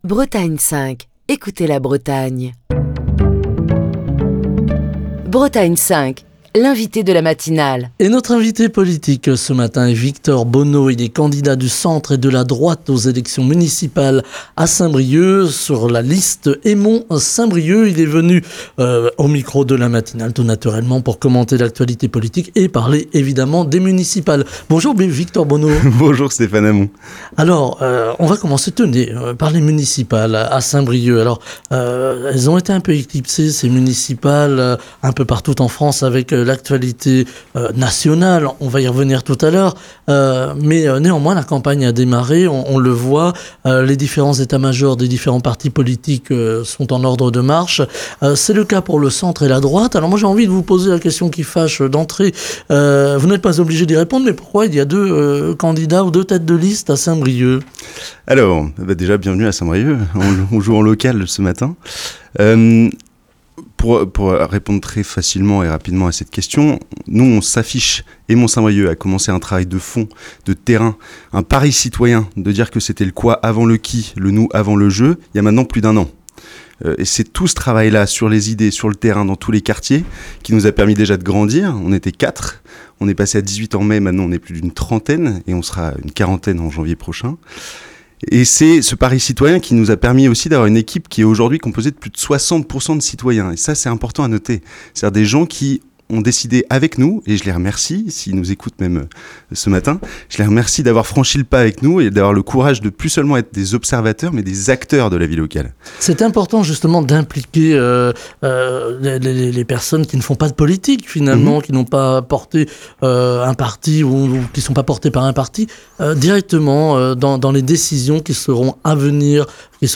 dans la matinale de Bretagne 5